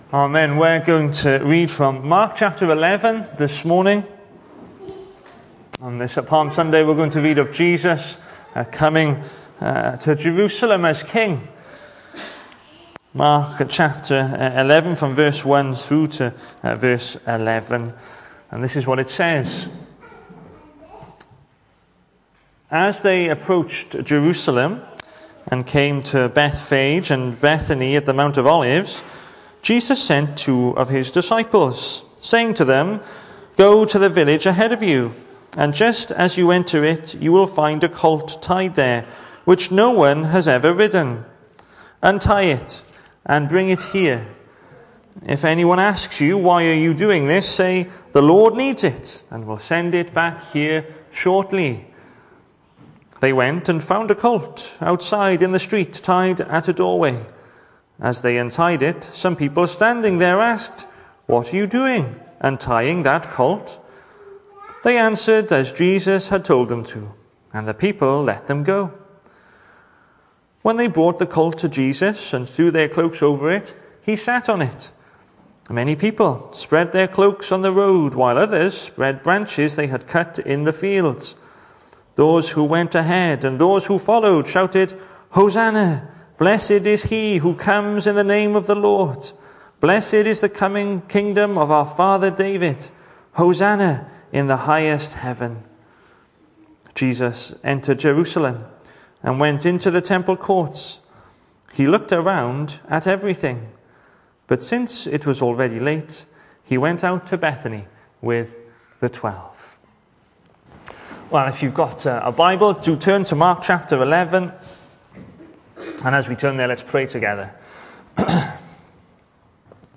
Hello and welcome to Bethel Evangelical Church in Gorseinon and thank you for checking out this weeks sermon recordings.
The 13th of April saw us host our Sunday morning service from the church building, with a livestream available via Facebook.